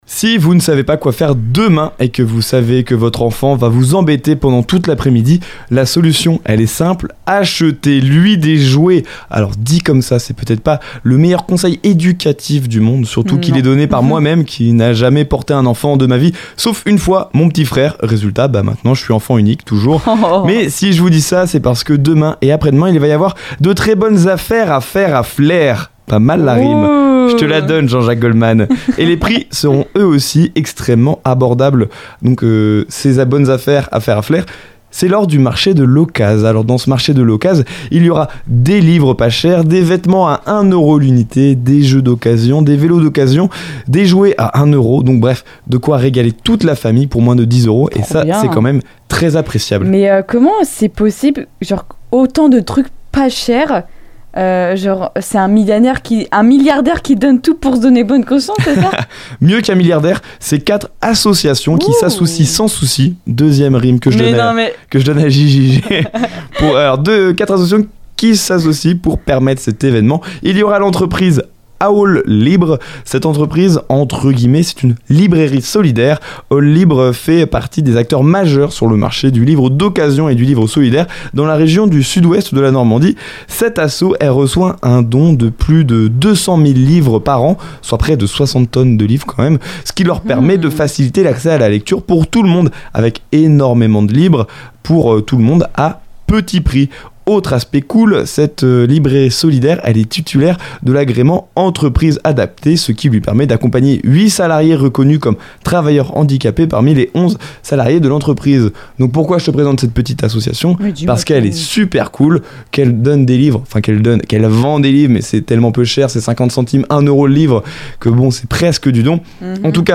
Le marché de l'occaz - Chronique